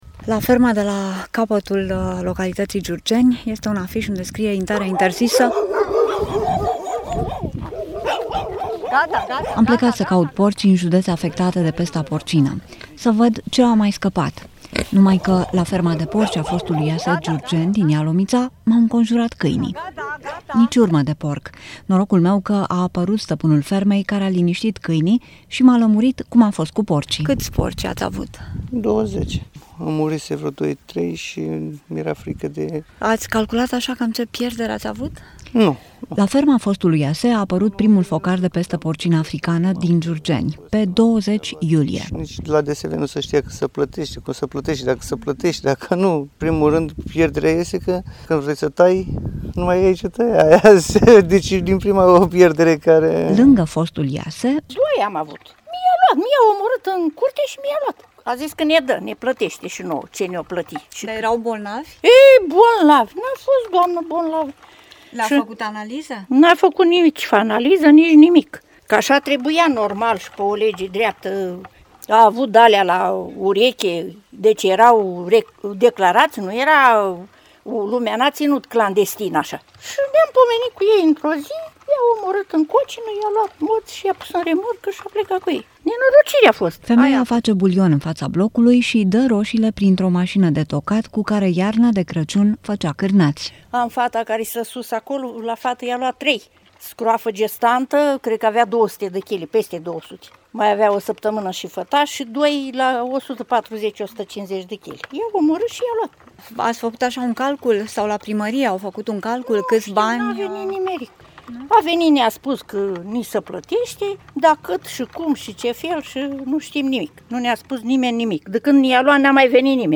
Reportaj: Ținutul fără porci